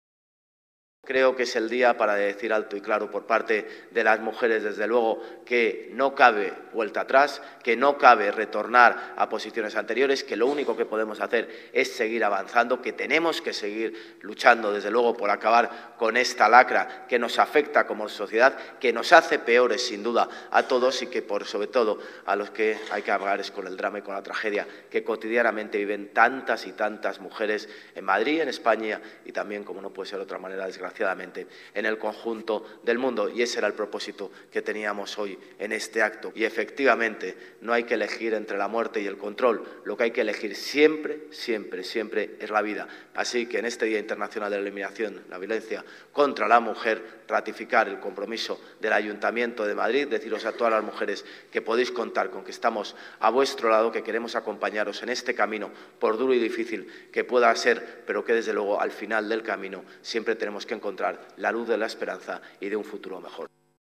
Nueva ventana:José Luis Martínez-Almeida, alcalde de Madrid